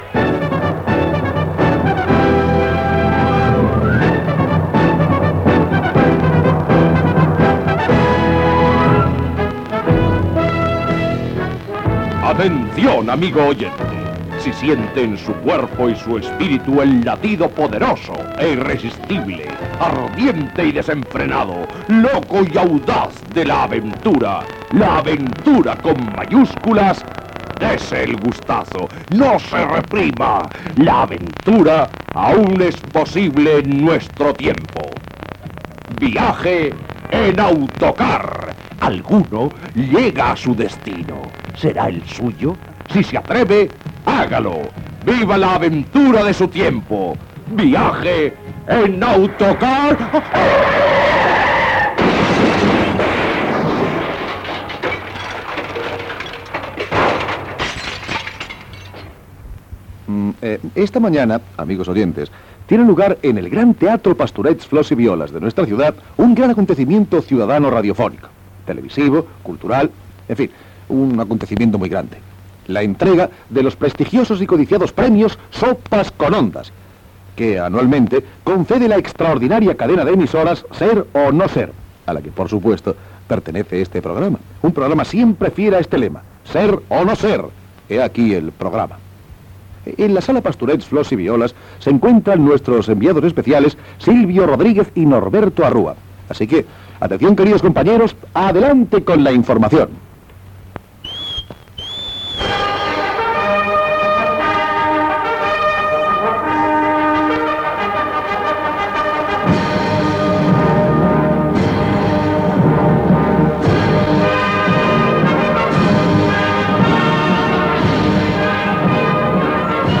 Gènere radiofònic Entreteniment
Banda FM